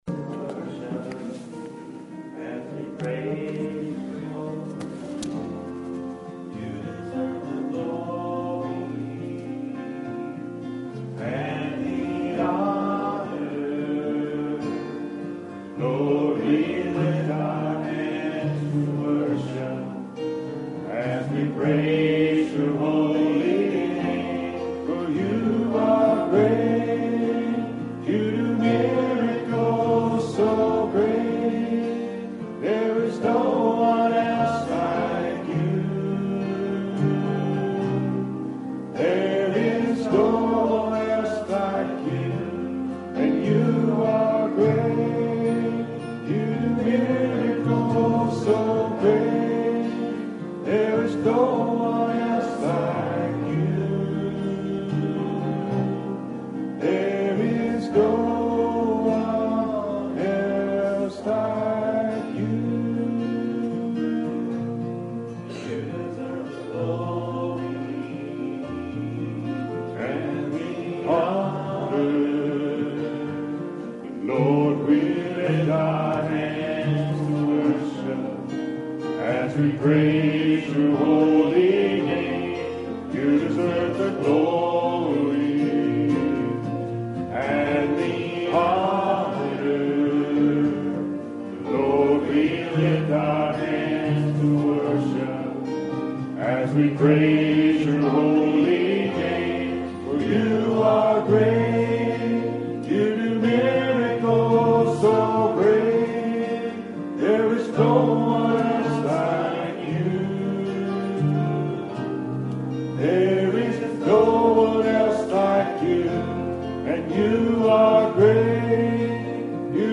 2015 Sermon Audio Archive